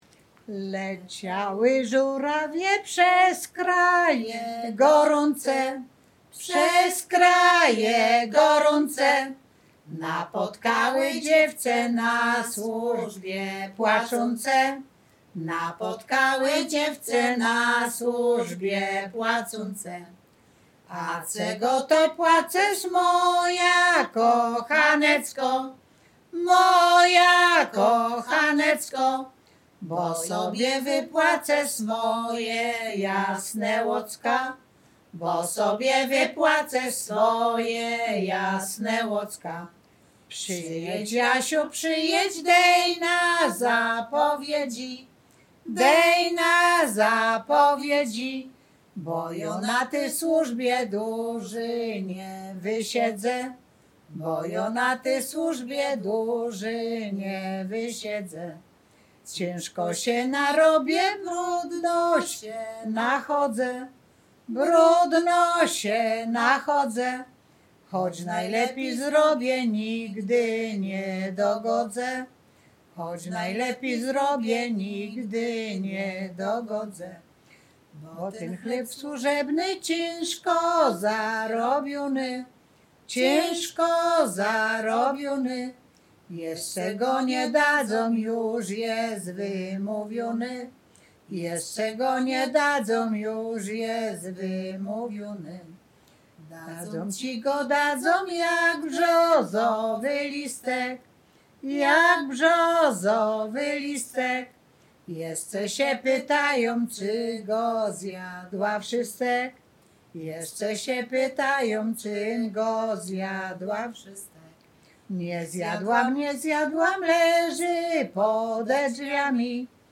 Śpiewaczki z Chojnego
województwo łódzkie, powiat sieradzki, gmina Sieradz, wieś Chojne
Kolęda